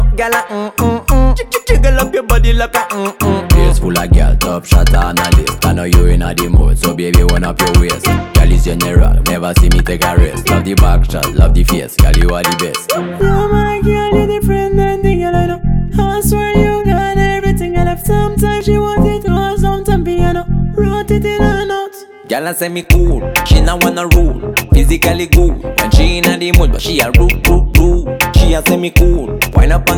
Жанр: Регги
Modern Dancehall, Reggae